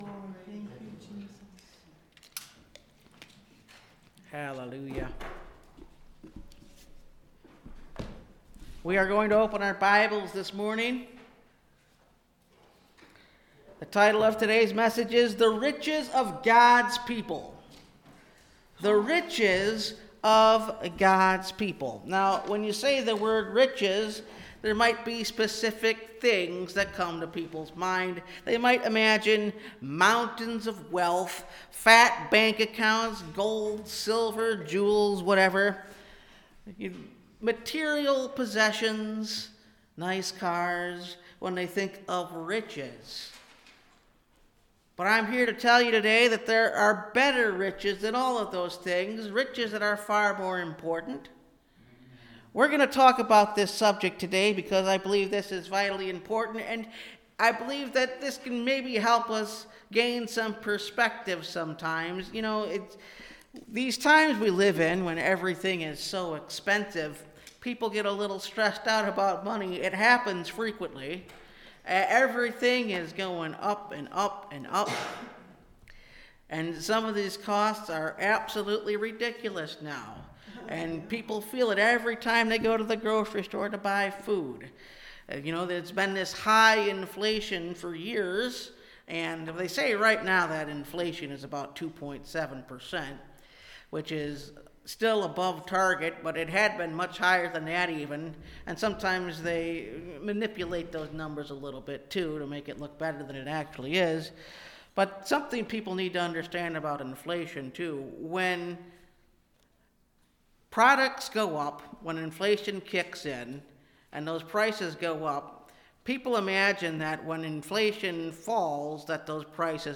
The Riches of God’s People (Message Audio) – Last Trumpet Ministries – Truth Tabernacle – Sermon Library